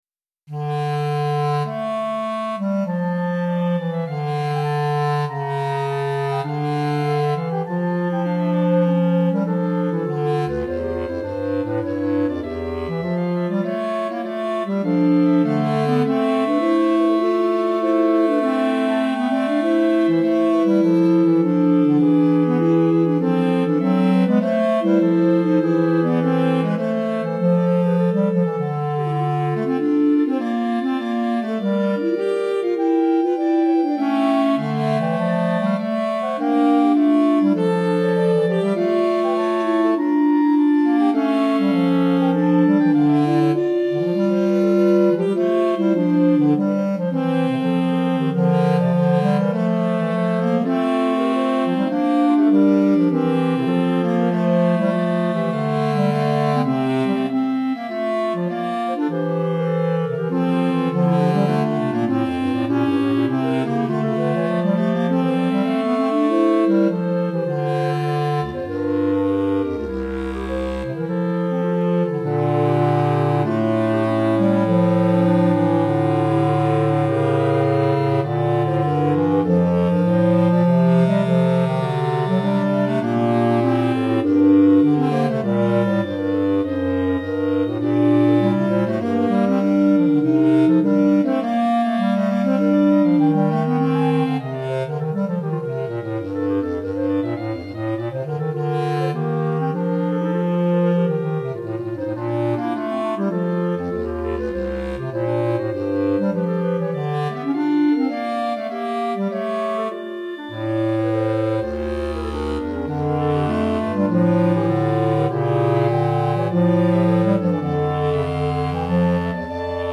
Répertoire pour Clarinette - 4 Clarinettes